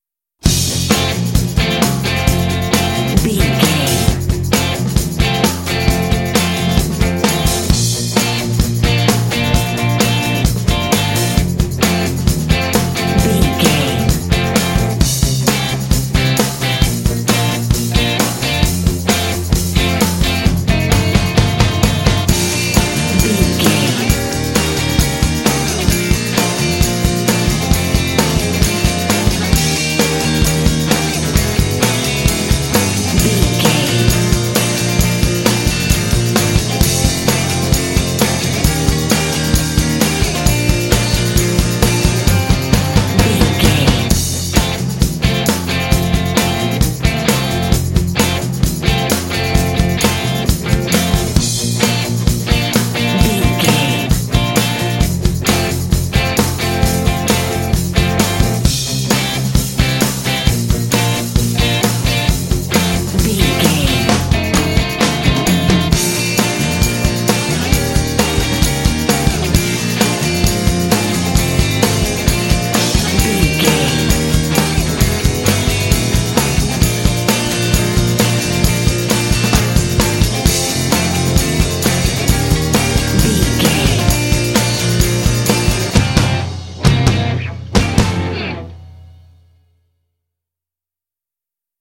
Uplifting
Ionian/Major
bouncy
happy
electric guitar
drums
bass guitar
saxophone